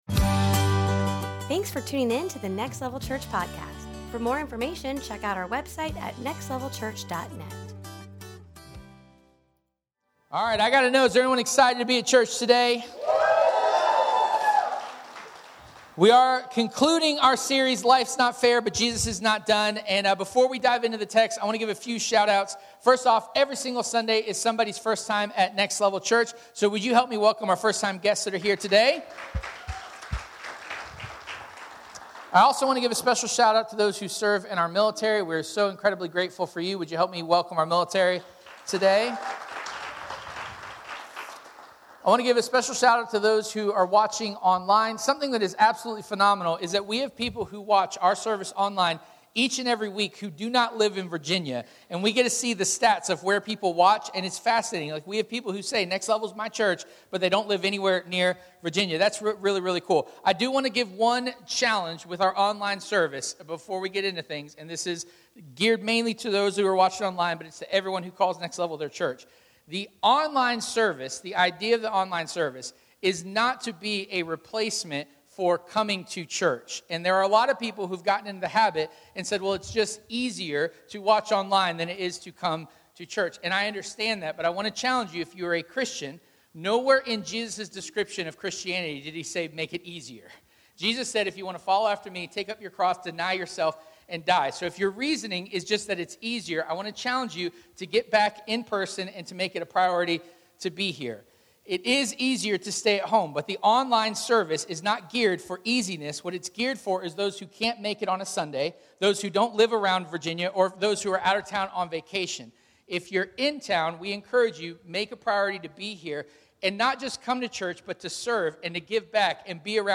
Service Type: Sunday Morning “That’s not fair” is one of the earliest phrases we learn as humans.